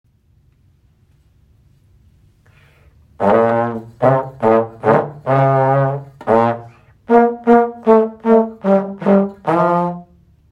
Vetopasuuna
Pasuuna.m4a